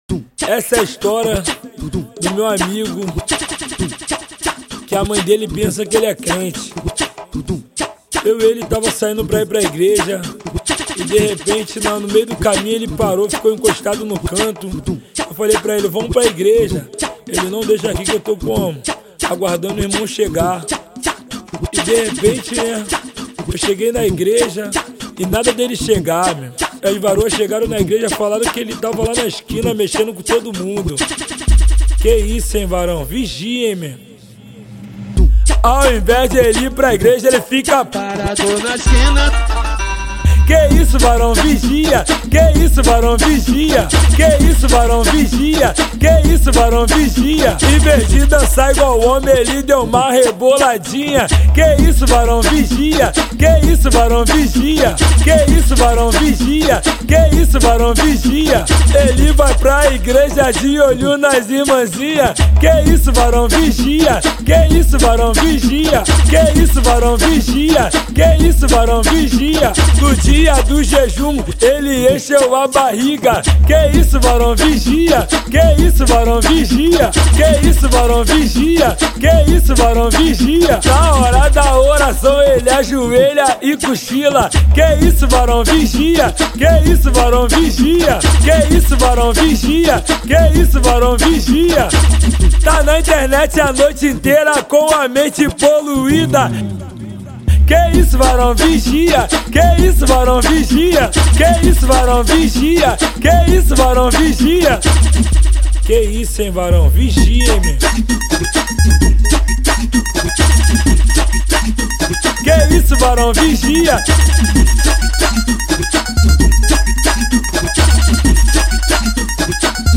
O cantor de funk